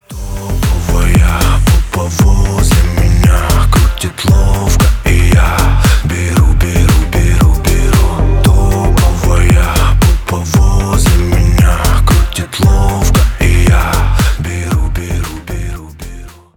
• Качество: 320 kbps, Stereo
Поп Музыка
клубные